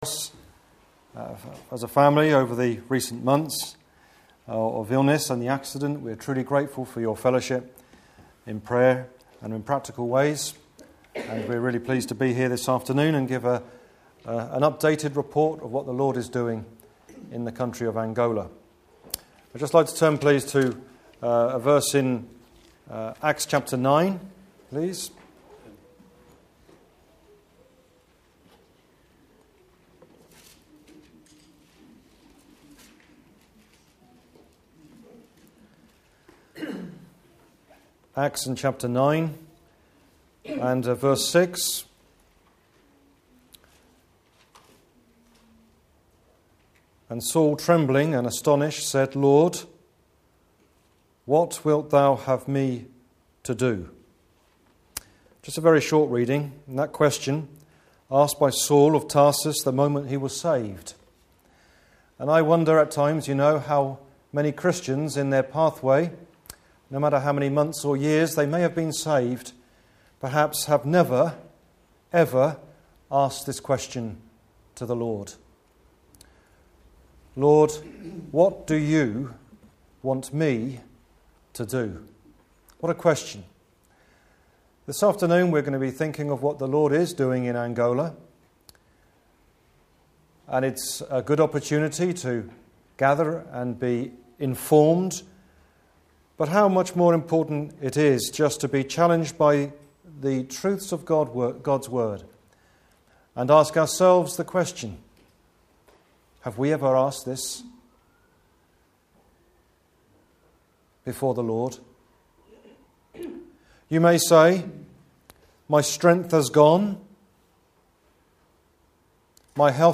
Annual Conference – 24th September 2011